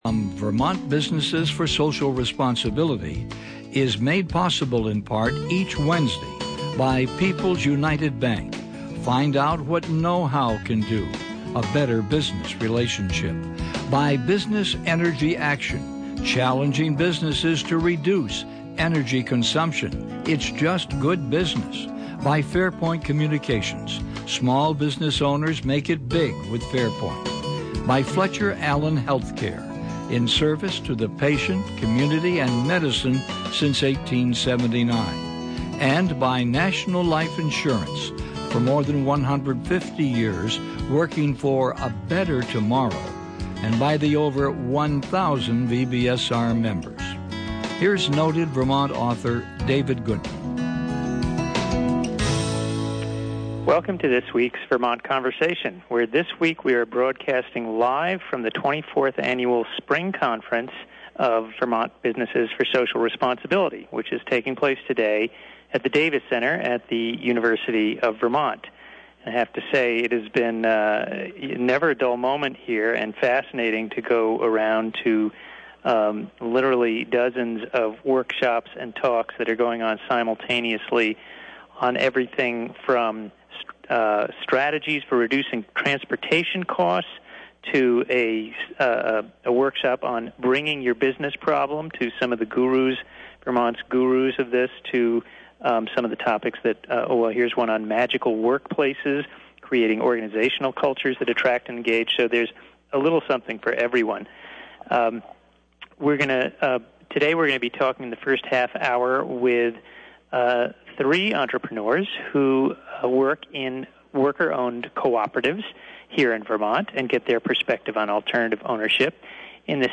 Live from the VBSR Spring Conference.